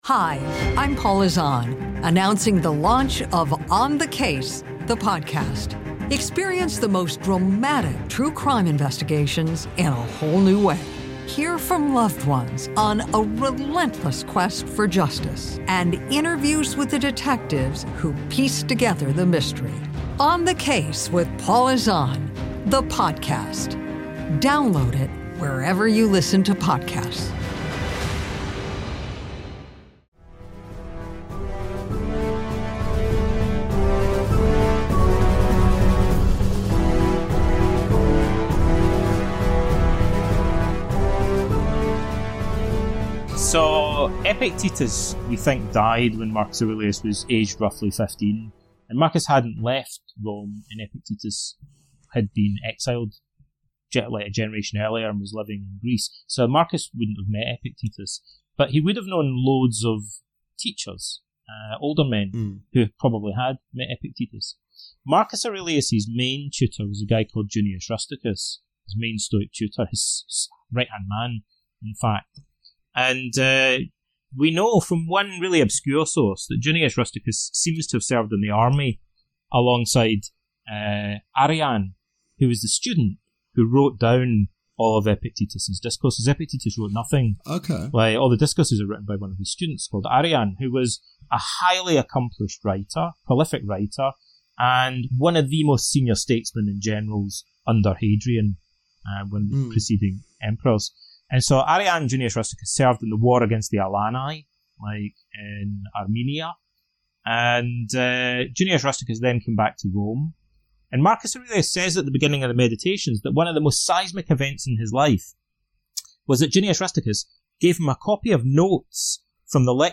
We chat about all things stoicism!